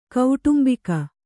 ♪ kauṭumbika